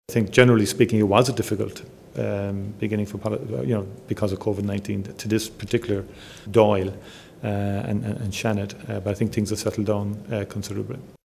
He says issues over meetings have now been sorted: